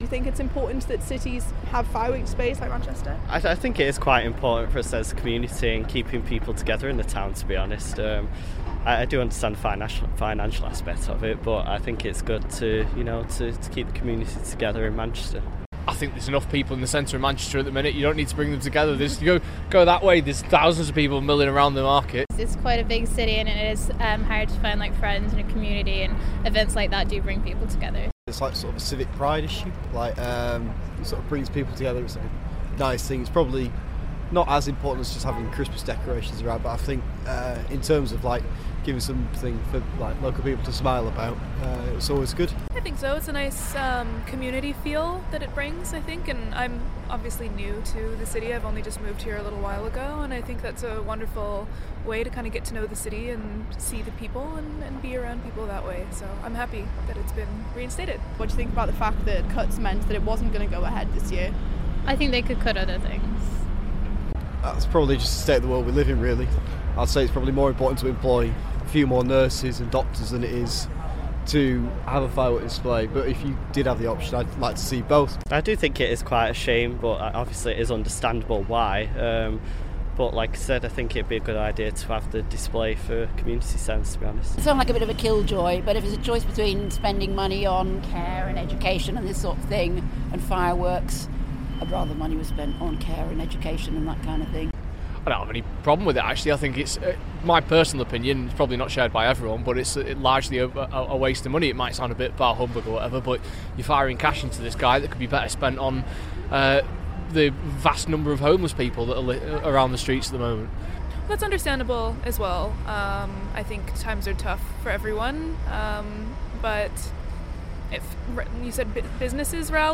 People react to news city centre will host new years eve firework display